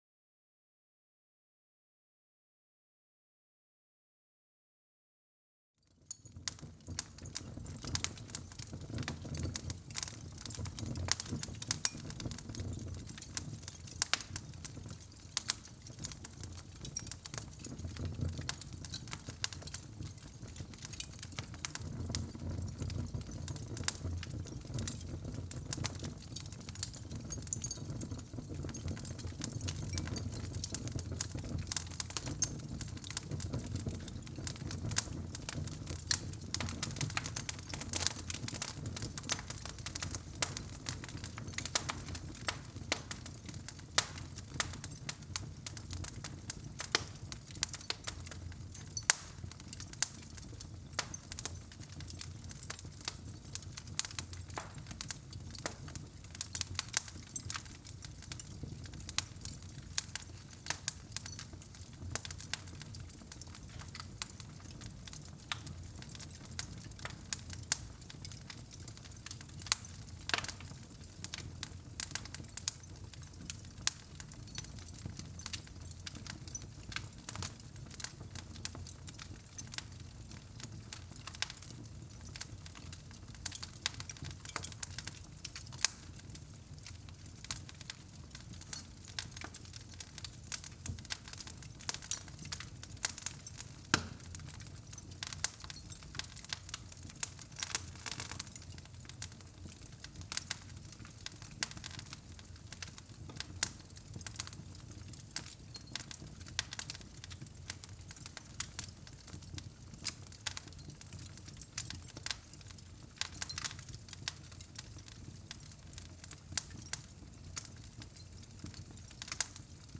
SFX_Scene04_FireBig.ogg